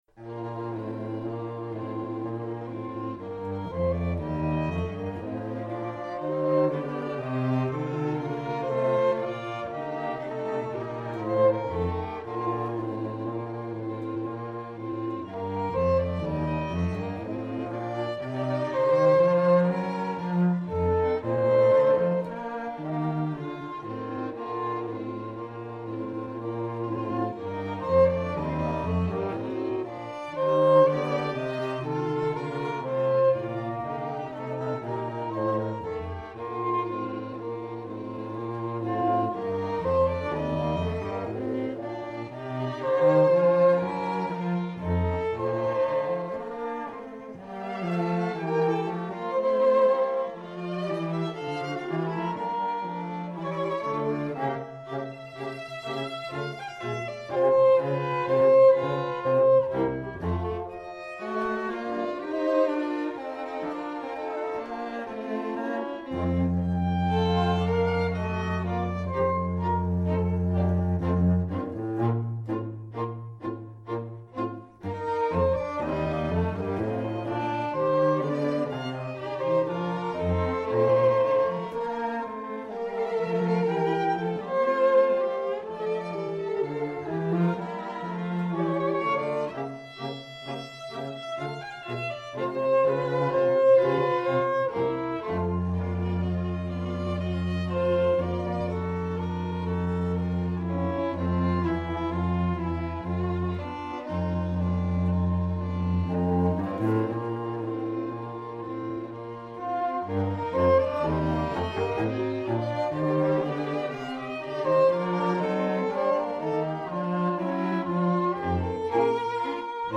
Soundbite 2nd Movt